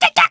Lakrak-Vox-Laugh_jp_b.wav